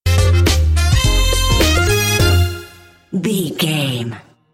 Short music, corporate logo or transition between images,
Epic / Action
Fast paced
In-crescendo
Uplifting
Ionian/Major
cheerful/happy
industrial
powerful
groovy
funky
synthesiser